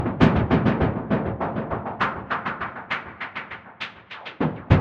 True Piano melody.wav
Original creative-commons licensed sounds for DJ's and music producers, recorded with high quality studio microphones.
truepianos_2__omd.ogg